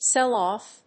séll óff